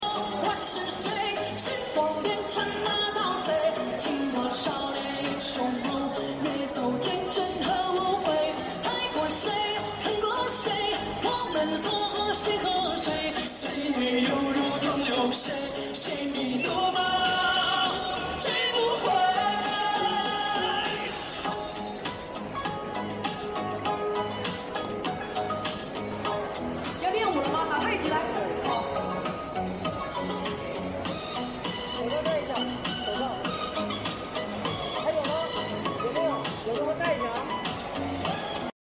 没出名之前的中国歌手唐艺 sound effects free download